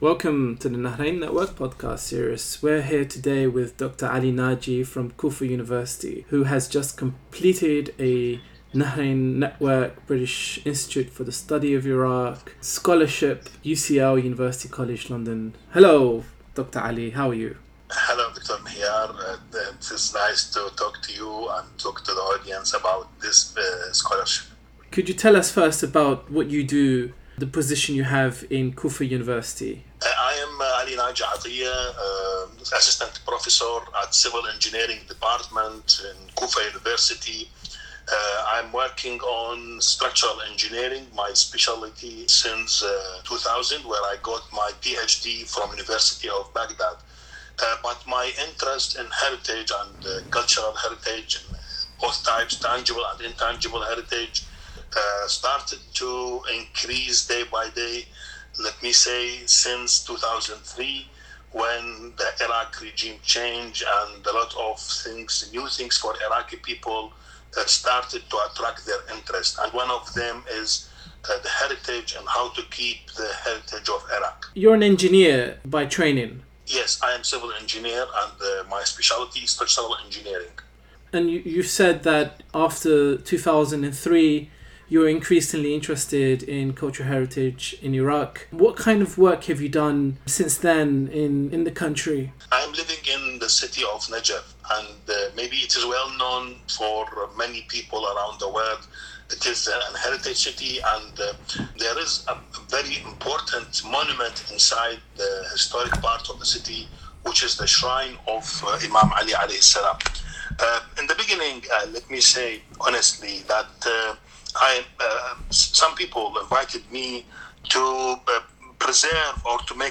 The Nahrein Network interviews scholarship awardee